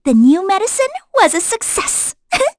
Mediana-Vox_Victory.wav